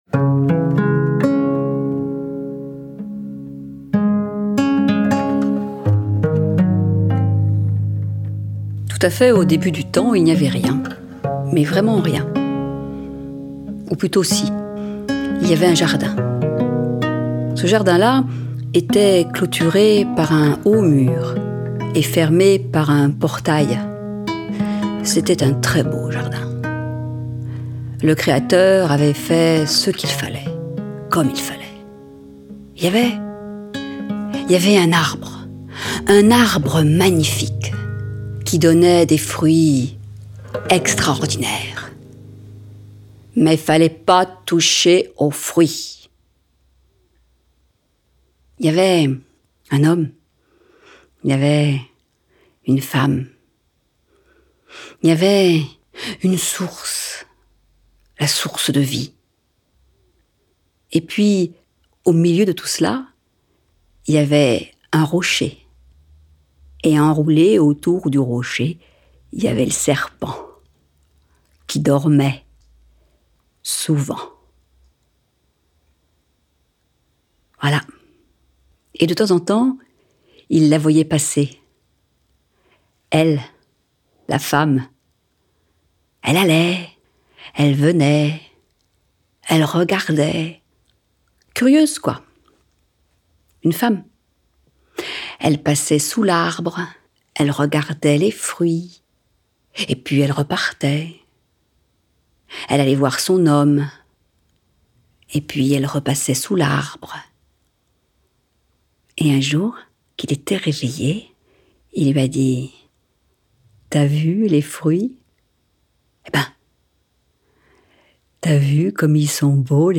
Contes d’auteurs
Avec sa voix chaude et envoûtante, la conteuse nous offre une promenade mystérieuse au pays de l’animal mythique et nous invite à apprivoiser la plus ancien...